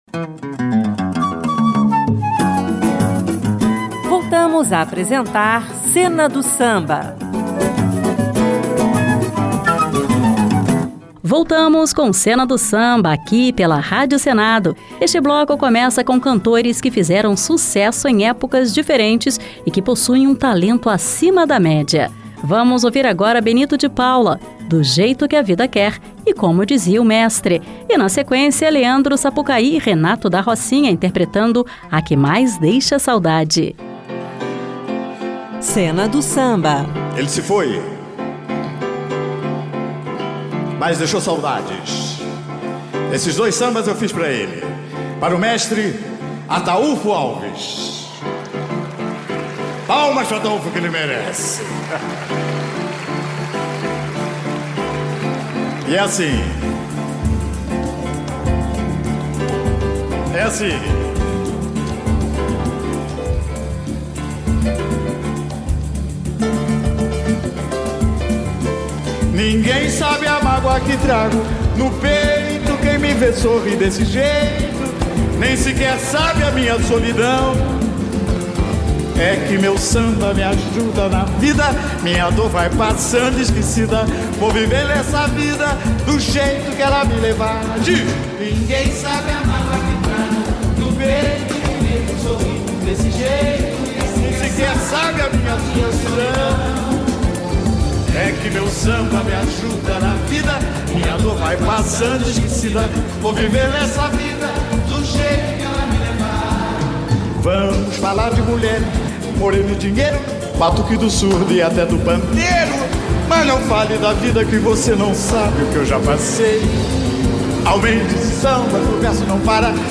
grandes cantoras